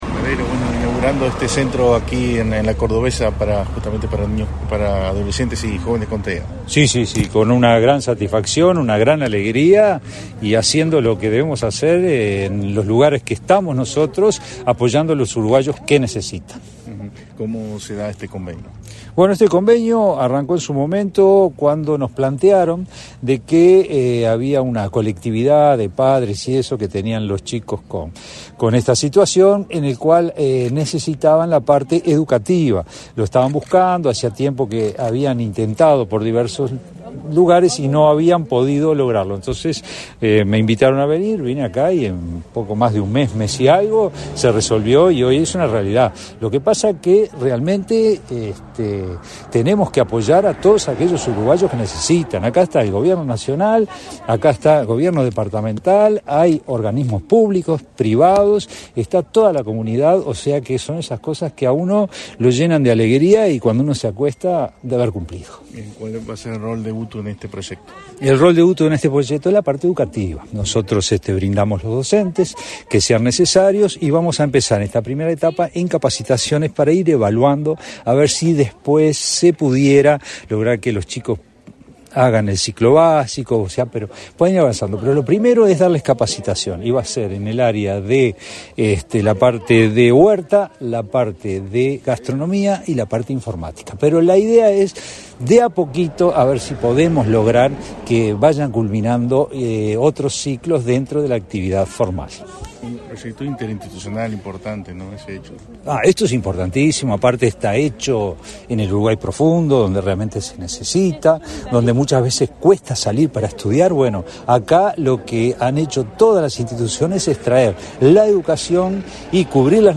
Declaraciones a la prensa del director de UTU, Juan Pereyra
Tras el evento, el director general UTU, Juan Pereyra, realizó declaraciones a la prensa.